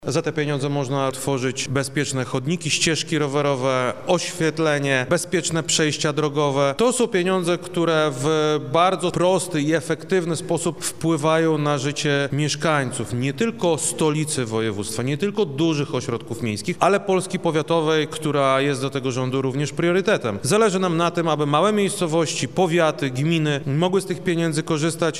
Krzysztof Komorski– mówi Krzysztof Komorski, wojewoda lubelski.